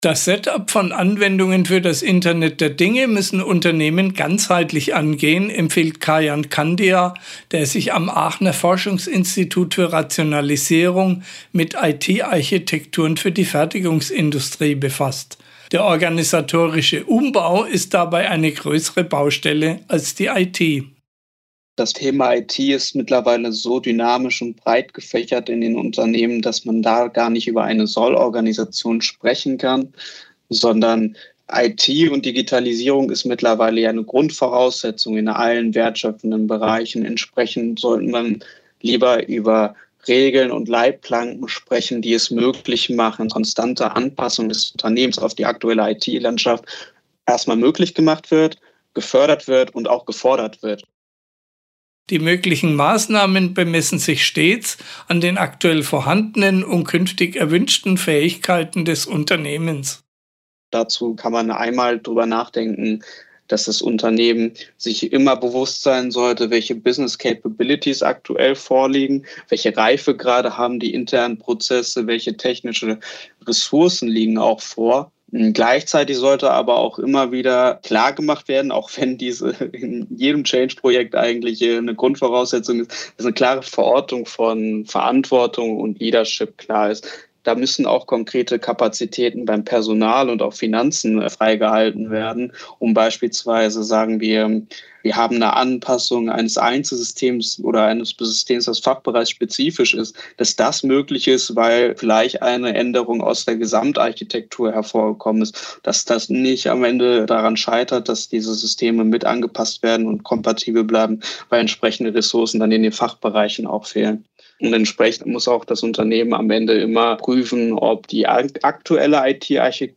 Für unsere Magazinreihe „IT-Matchmaker.guides“ führen unsere Redakteure regelmäßig Experten-Interviews zu aktuellen Themen rund um Business Software.